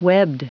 Prononciation du mot webbed en anglais (fichier audio)